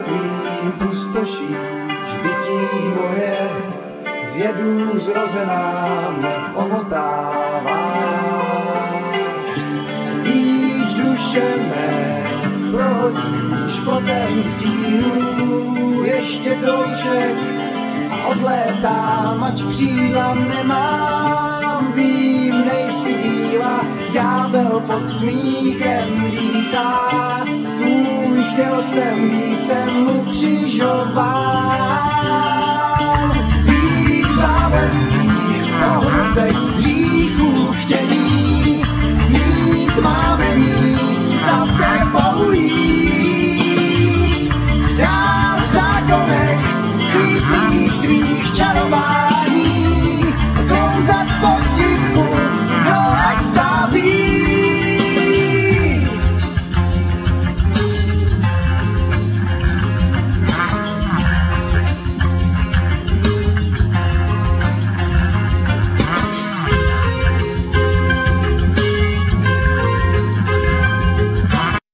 Voice
Drums,Petra cigarets
Bass
Guitar
Cimbalum,Vocal
Saxophone,Bassoon
Violin